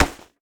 Pads Hit Normal.wav